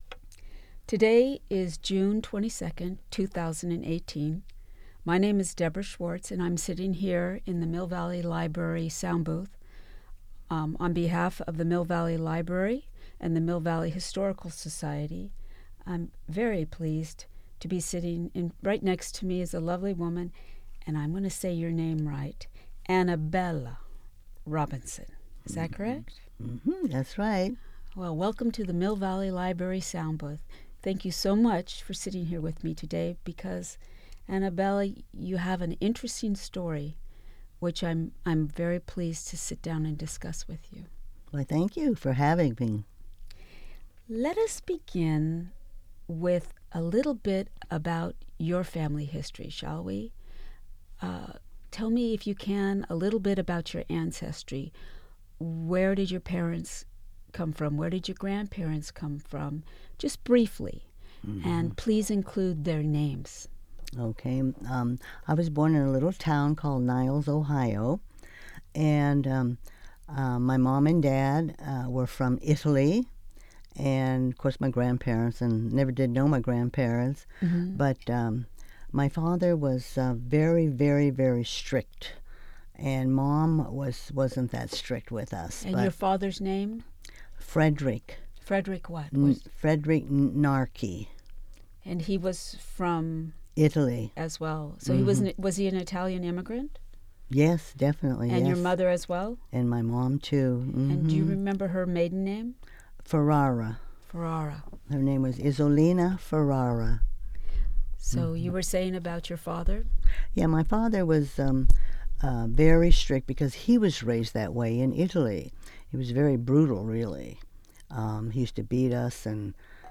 Oral History
Editor’s note: This interview includes mature content.